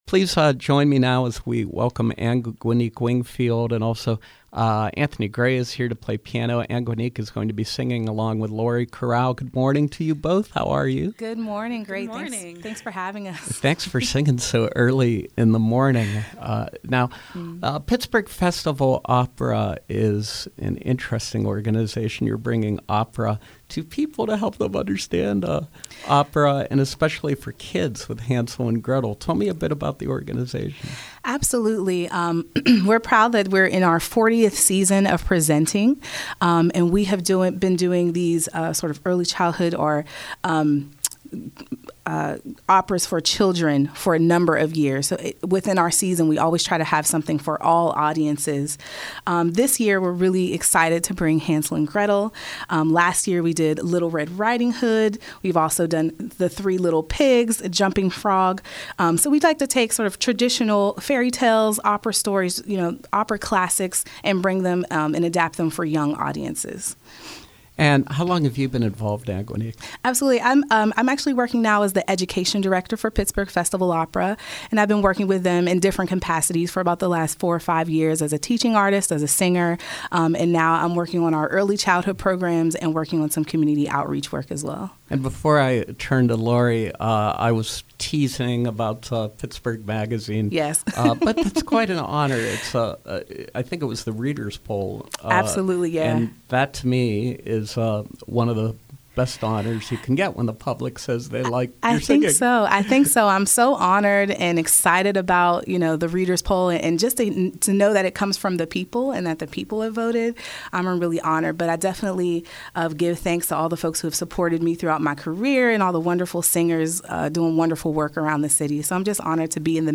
In-Studio Pop Up: Pittsburgh Festival Opera
duet
piano